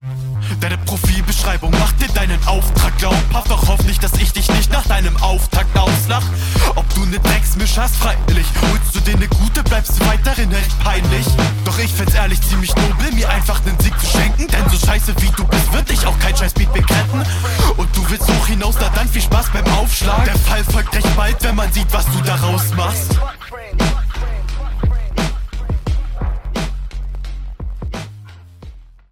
Kommst dope auf den Beat, gute punches mit gegnerbezug und hört sich nice an.